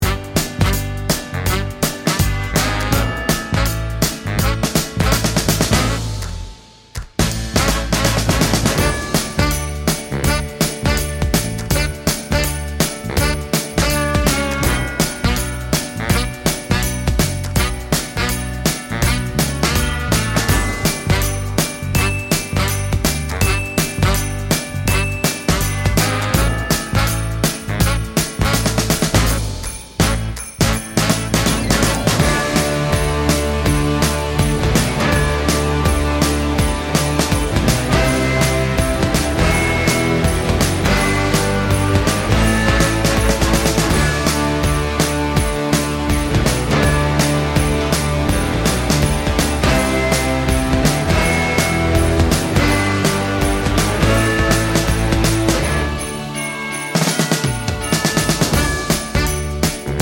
no Backing Vocals Christmas 3:22 Buy £1.50